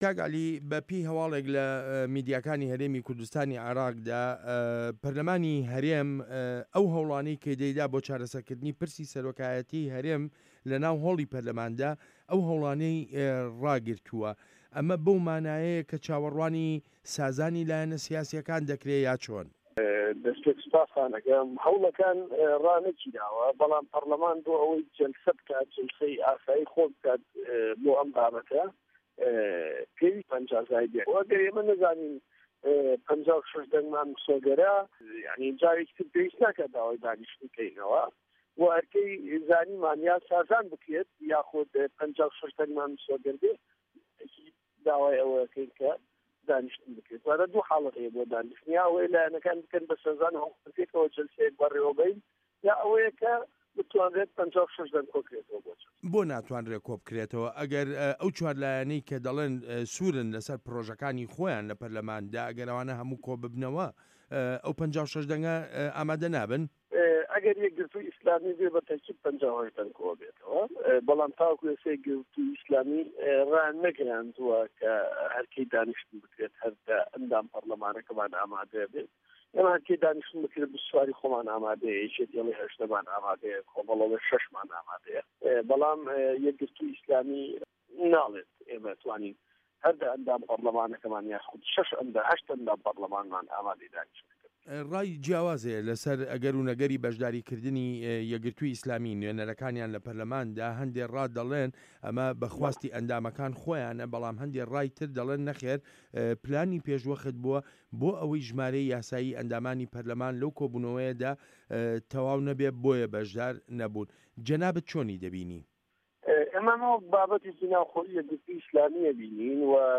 وتووێژ لەگەڵ عەلی حەمە ساڵح